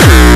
VEC3 Bassdrums Dirty 11.wav